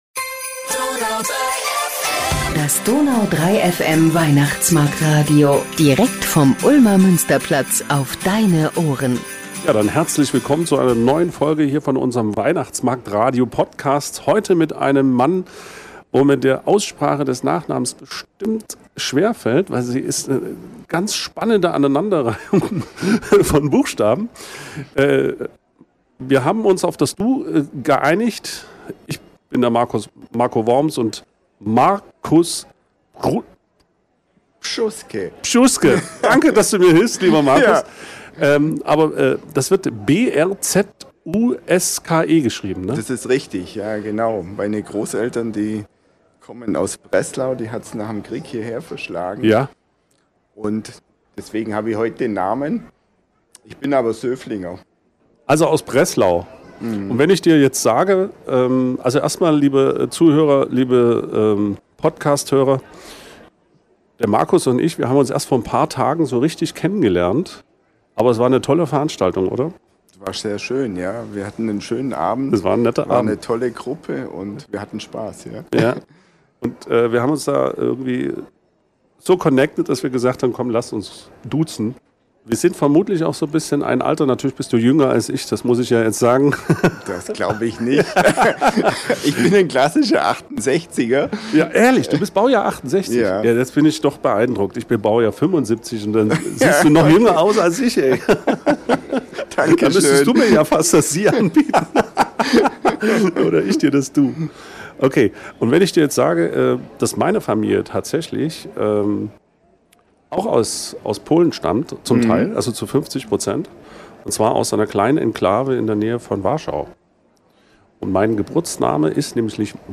Das DONAU 3 FM Weihnachtsmarkt- Radio